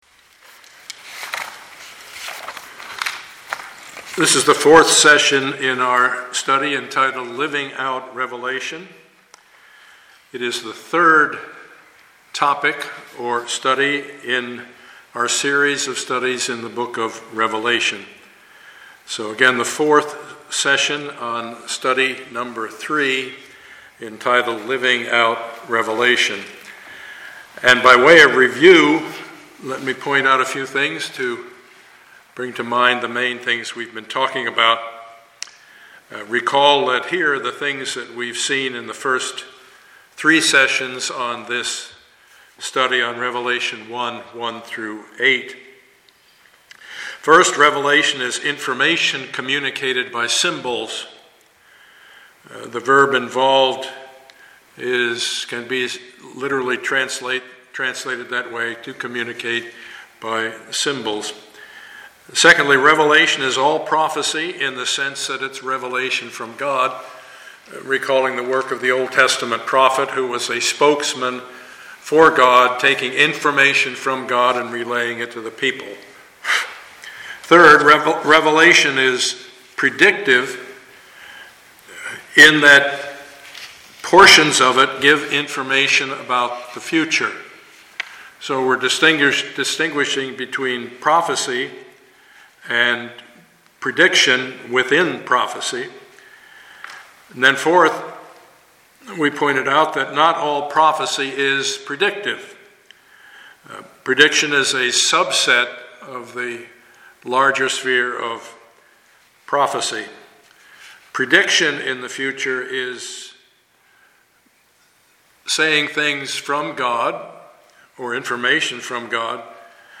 Passage: Revelation 1:1-8 Service Type: Sunday morning